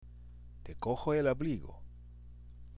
（テコホ　エッラブリーゴ）